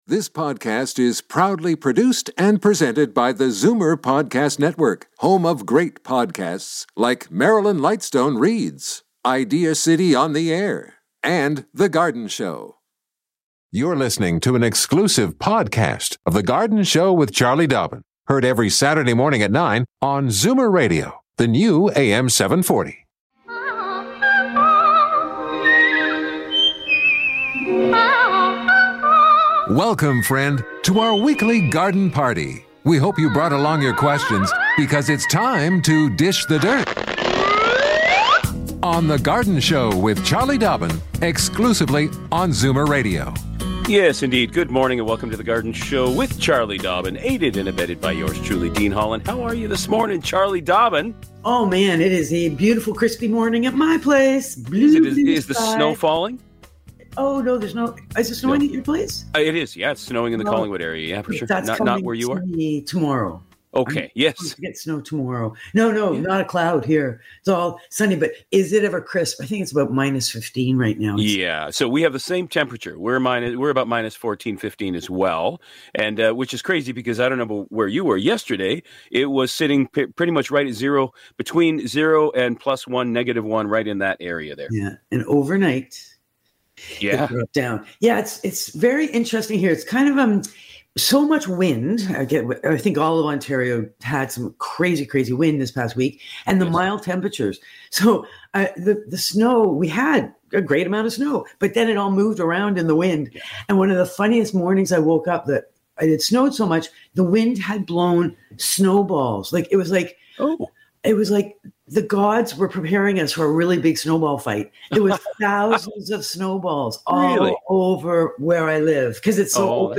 Keeping Amaryllis healthy and blooming for many years is easy - fertilizing, re-potting and more. We discuss soggy orchid care plus a caller is searching for specific Chrysanthemums. Another caller has a good suggestion!